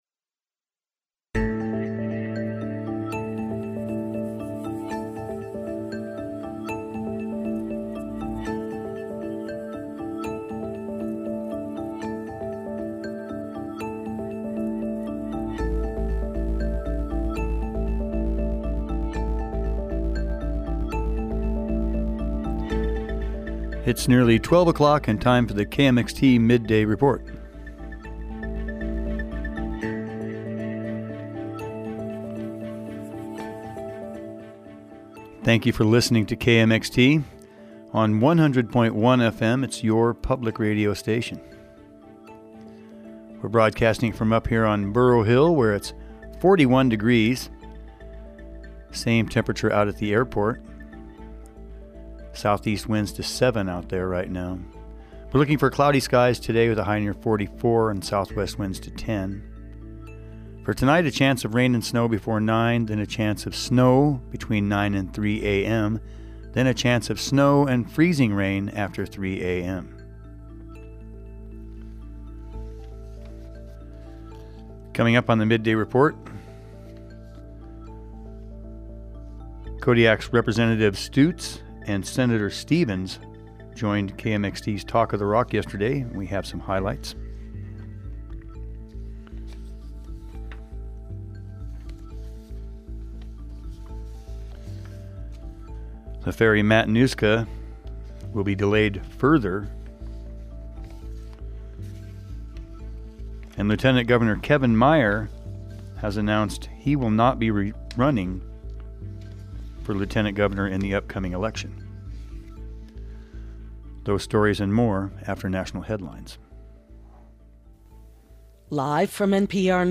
KMXT Staff December 29, 2021 News, Newscasts, Newsflash